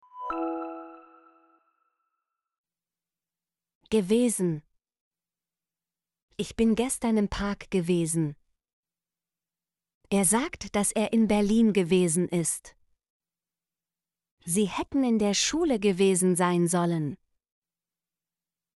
gewesen - Example Sentences & Pronunciation, German Frequency List